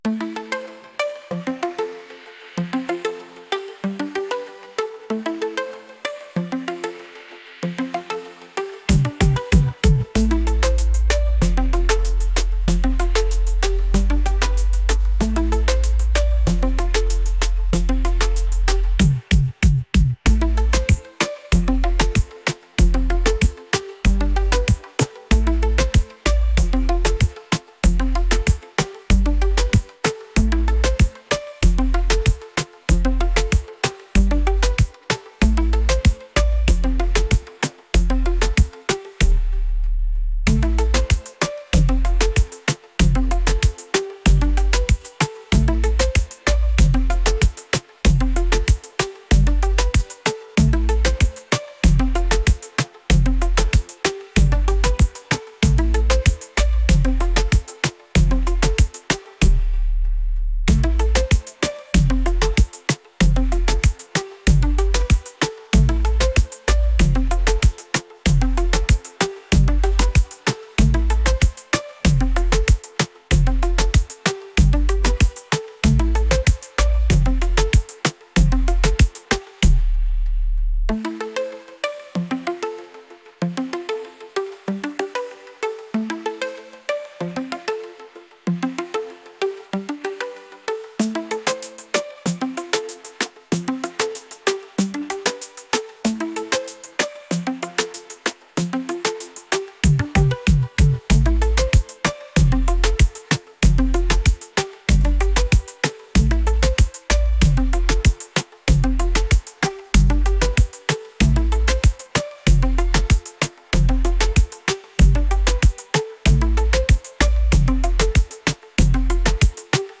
upbeat | energetic